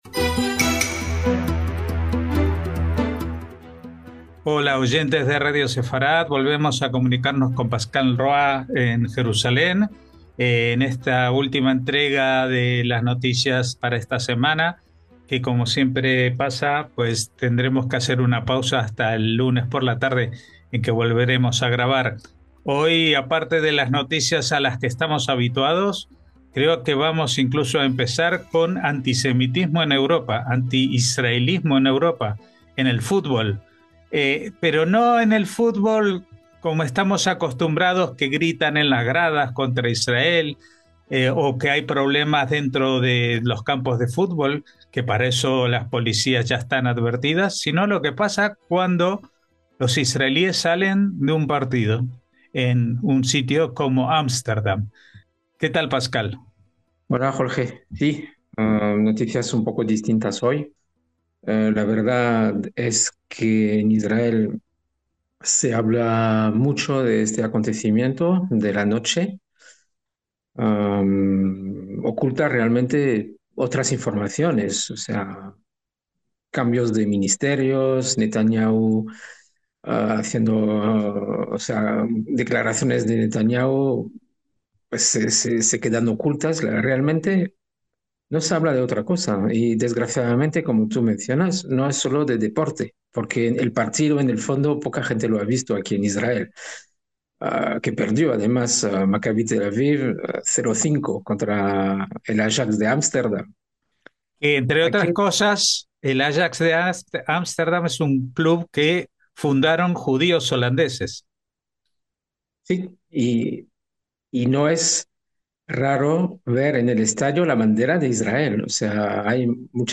NOTICIAS CON COMENTARIO A DOS - Aún nos cuesta creer que ha pasado lo que ha pasado en el siglo XXI, en plena Europa, a escasos kilómetros de aquí: un pogromo antisemita coordinado desde el islamismo radical asentado en países como Holanda, disfrazado de reivindicación palestina.